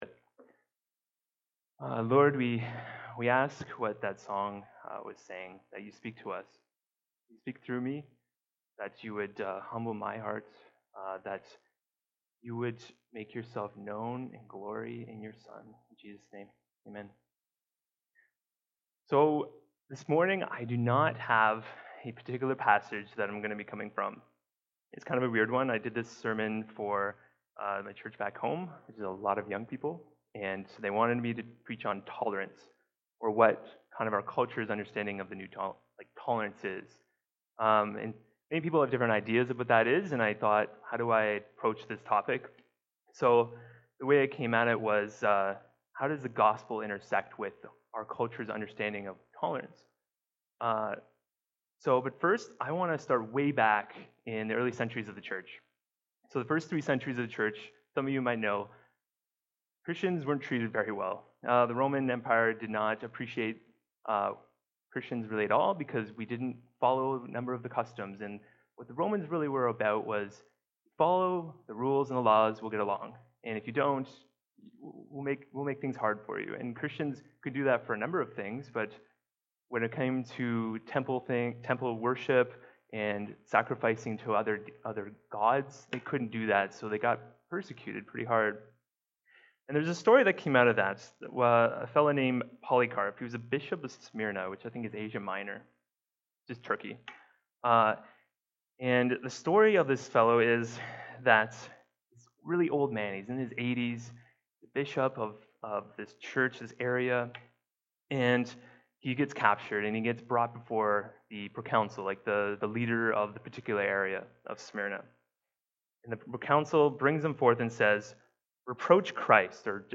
Byron Community Church is an Evangelical Chuch located in the south west side of London, Ontario
Weekly Sermons